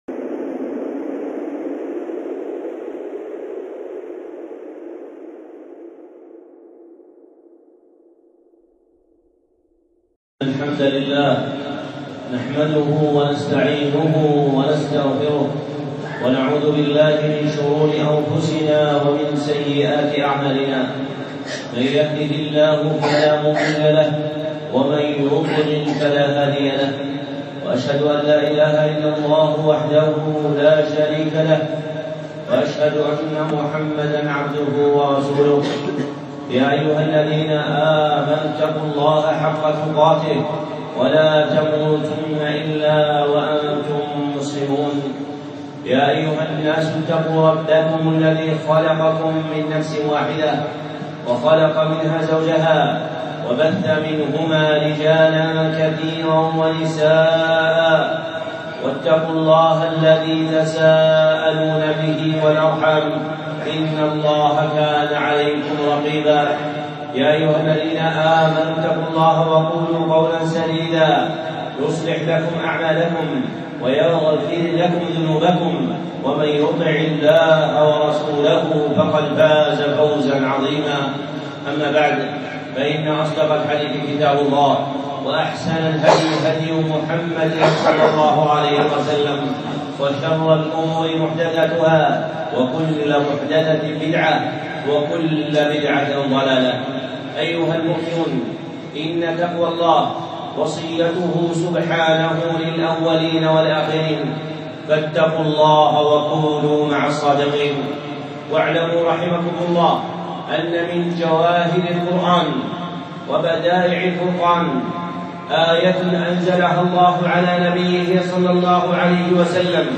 خطبة (آية عرفة)